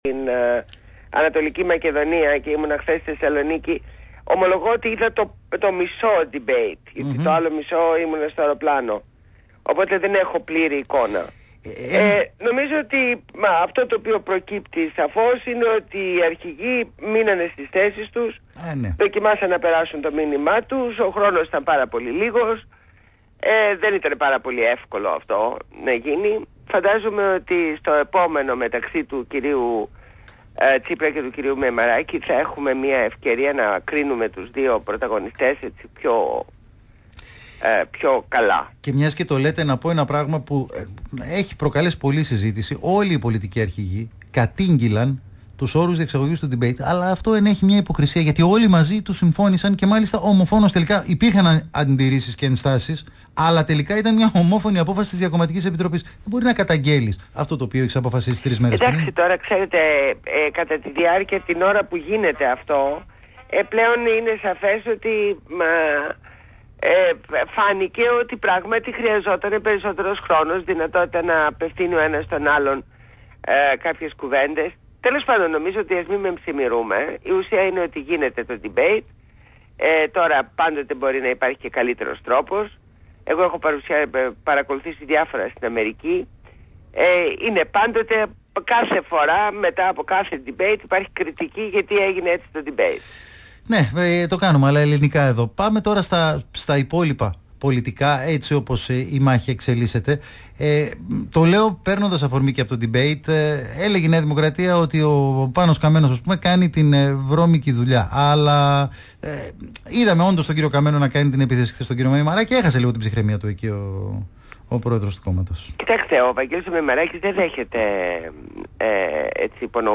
Συνέντευξη στο ραδιόφωνο Παραπολιτικά 90,1fm στο δημοσιογράφο Ν. Ευαγγελάτο.